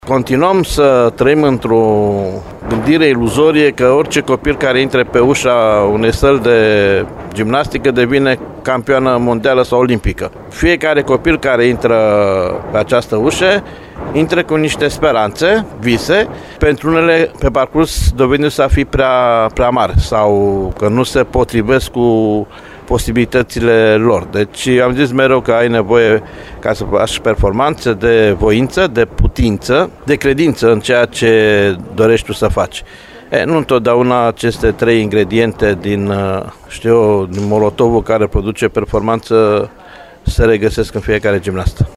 Antrenorul coordonator